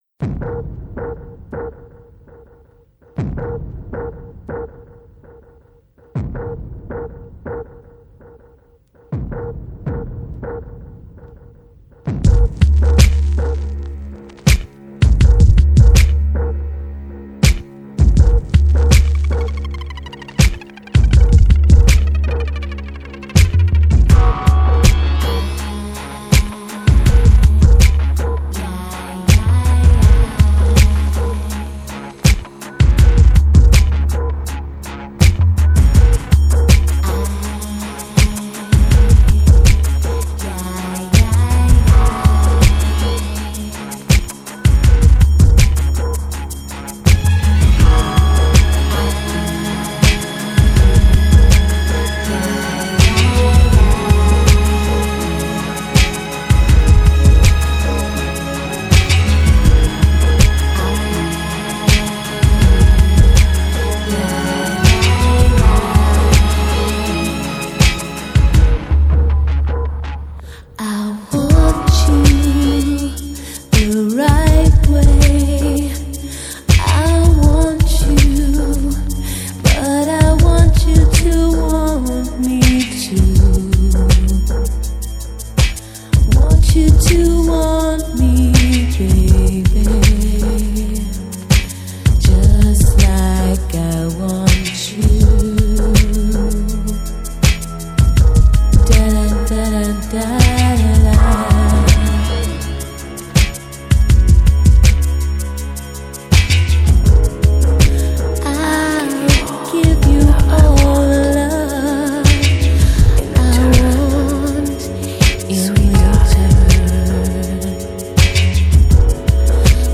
Pop, Ballad